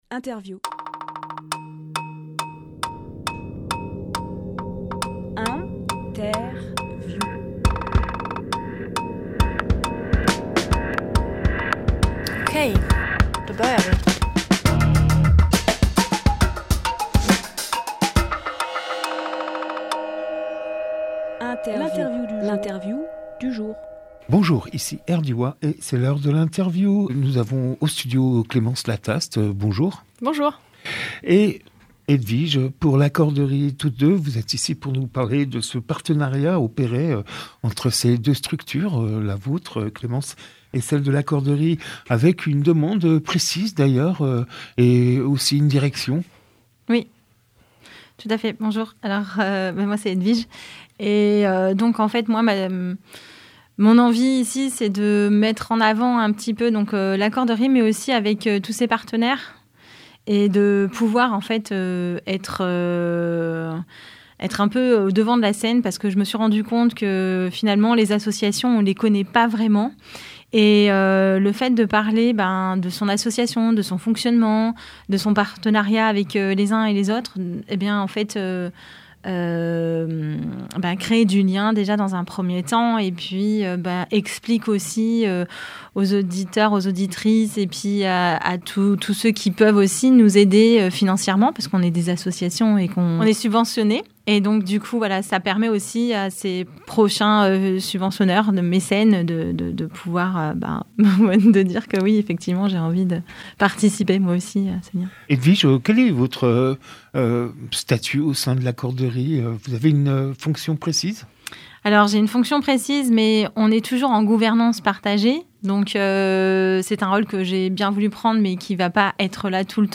Emission - Interview
Lieu : Studio Rdwa